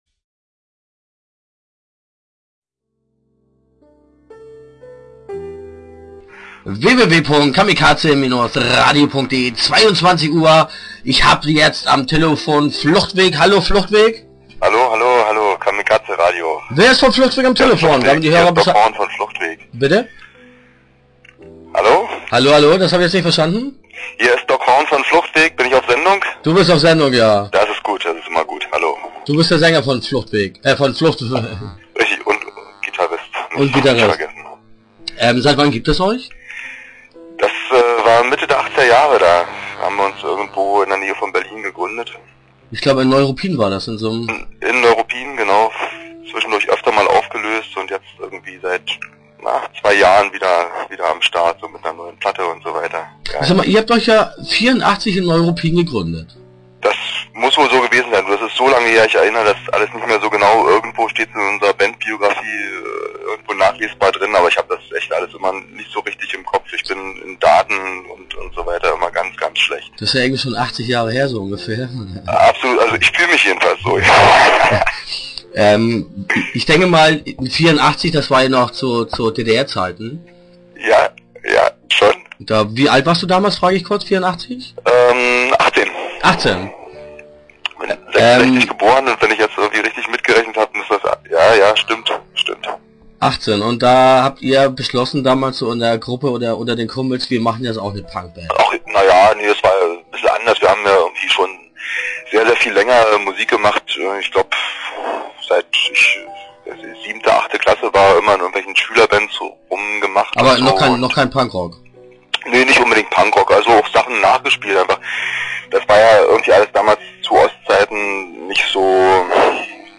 spricht am Telefon
Interview Teil 1 (10:14)